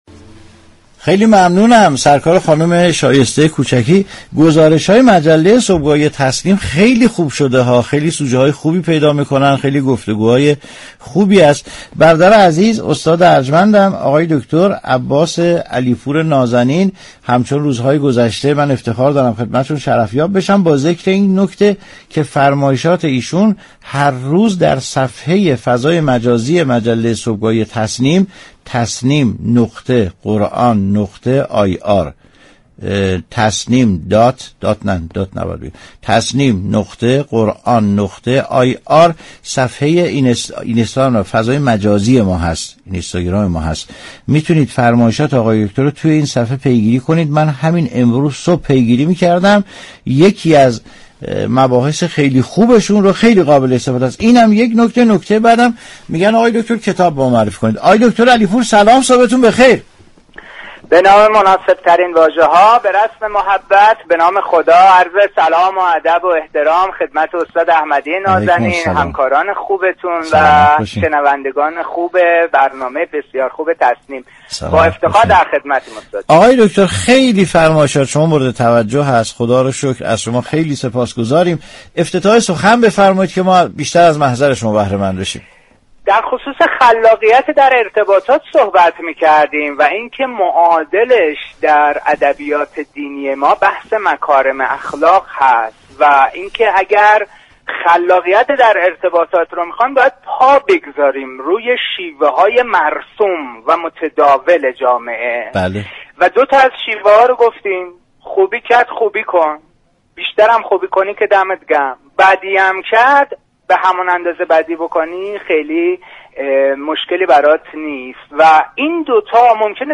در گفتگو با برنامه تسنیم رادیو قرآن